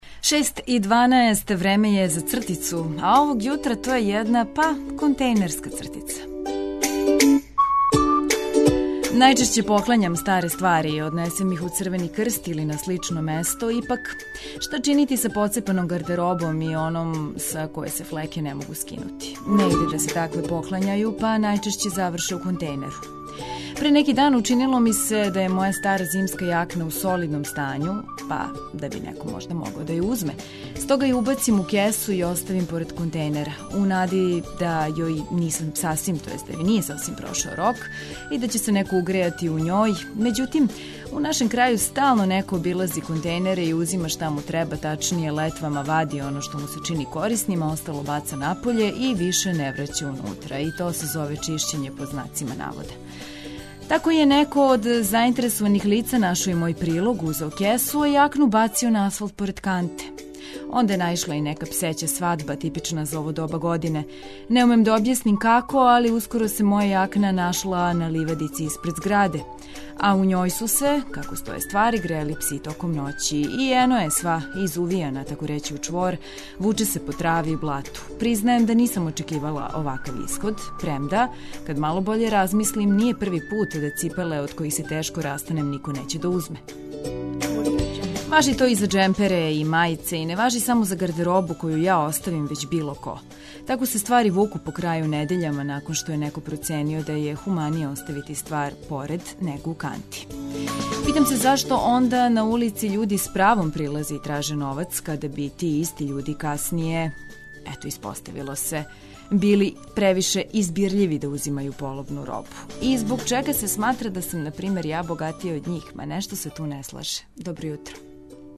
Ново сунчано јутро уз јутарњи програм Београда 202